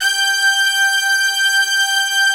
G5 POP BRA.wav